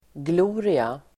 Ladda ner uttalet
gloria substantiv (bildligt " helgonskimmer"), halo [figuratively, " aura of sanctity"] Uttal: [gl'o:ria] Böjningar: glorian, glorior Definition: strålkrans (kring huvudet på helgon) (the radiant light often depicted around the head of a divine or sacred personage)